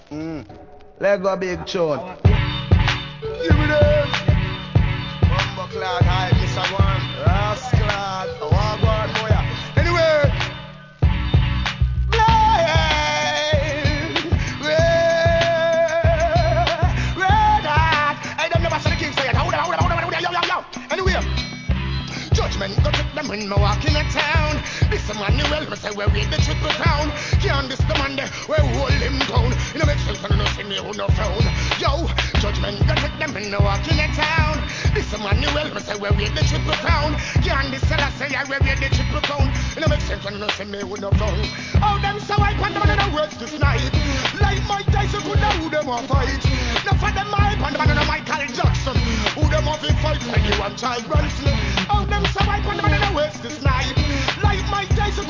HIP HOP X REGGAE MUSH UP物!!!